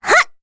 Rehartna-Vox_Jump1_kr.wav